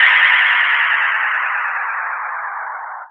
Drop_FX_5_C3.wav